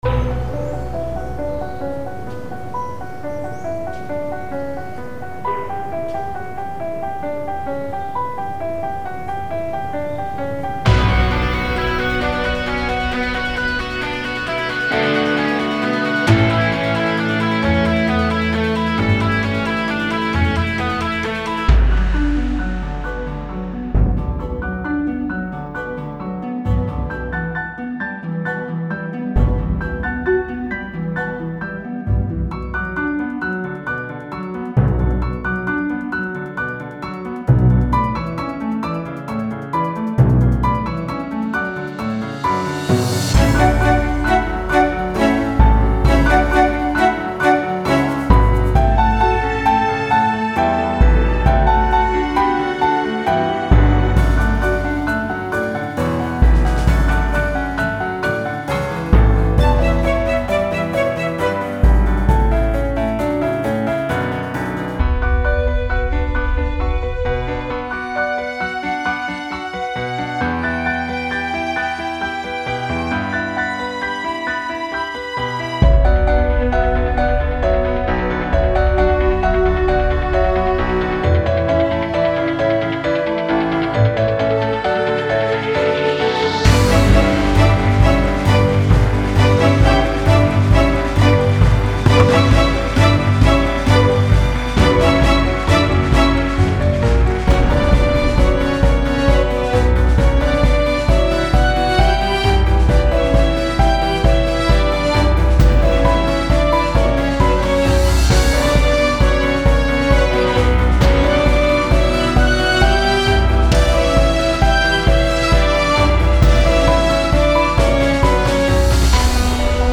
Плохо сведенное музло
Он очень тонко звучит. Еще мне очень не нравится сухорукая гитара, это тоже плохо.